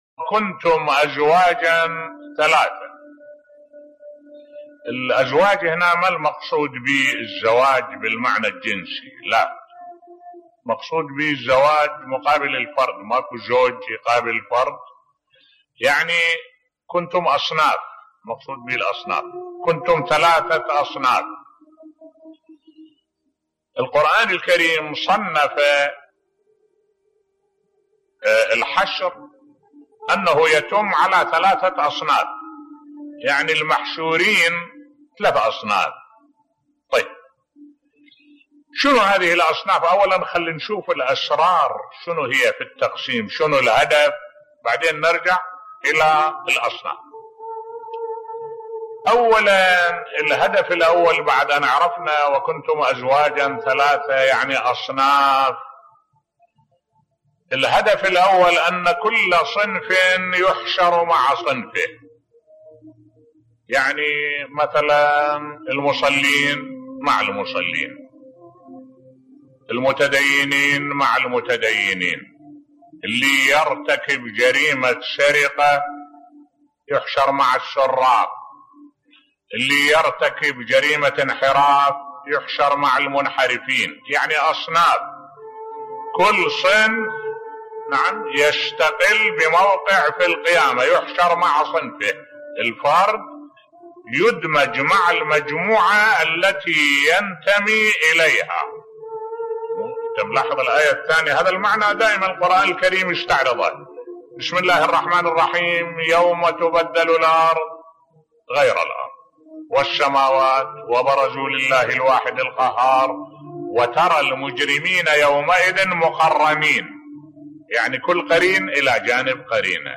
ملف صوتی كيف صنف الله الناس يوم القيامة بصوت الشيخ الدكتور أحمد الوائلي